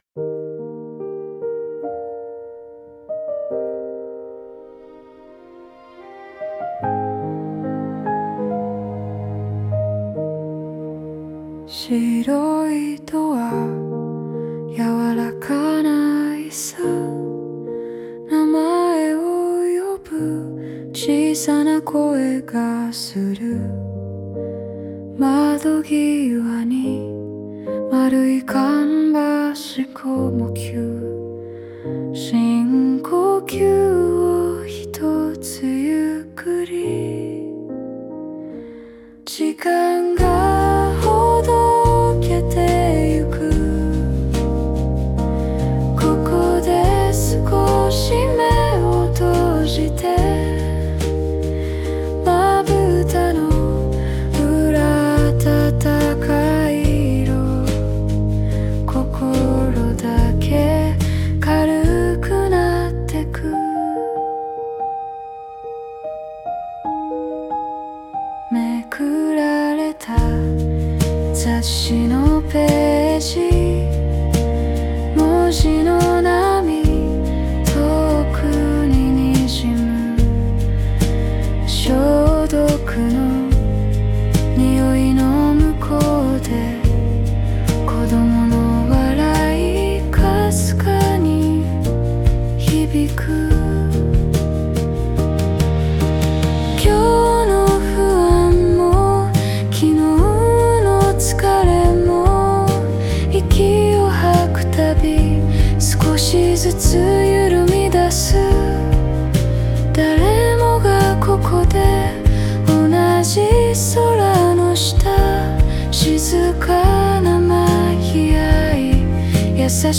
ゆるりと心が軽くなる、穏やかな曲です。